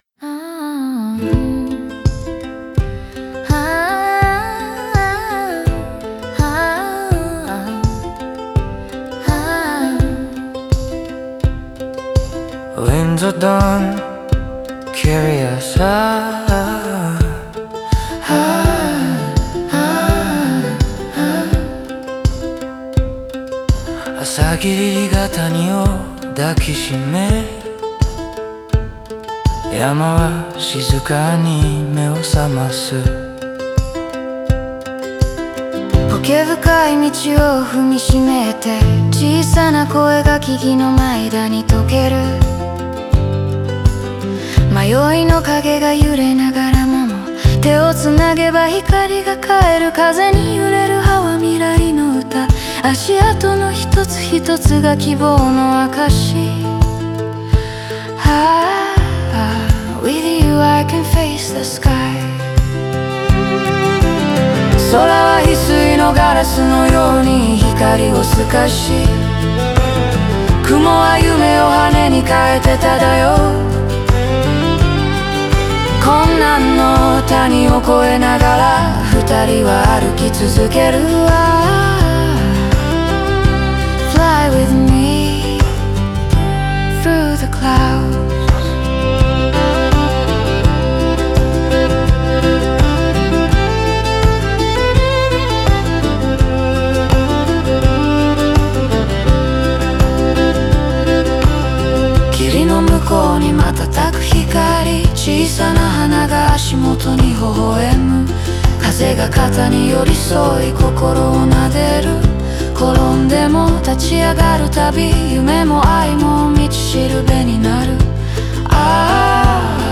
英語フレーズをアクセントに加えることで幻想的な空気を演出し、文学的でリズミカルな語感を持たせている。